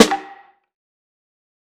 HFMSnare8.wav